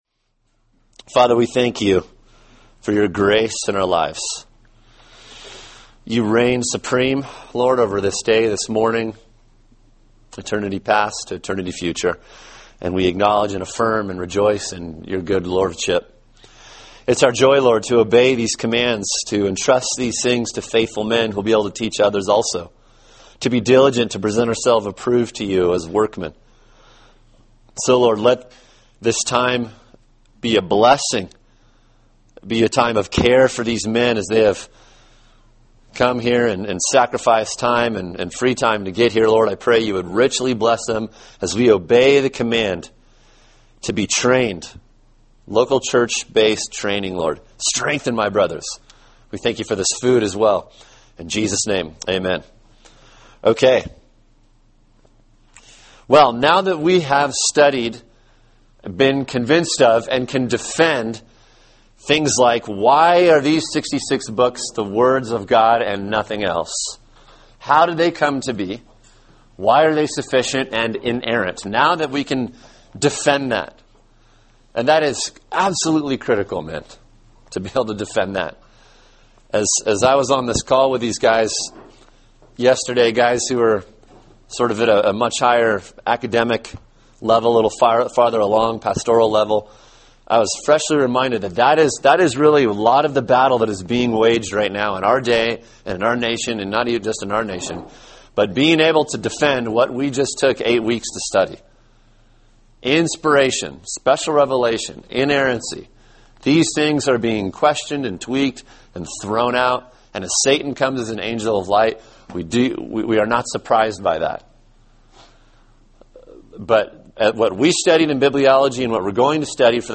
Hermeneutics Lesson 1: Introduction to Biblical Interpretation – What is Hermeneutics? What is the goal of biblical interpretation?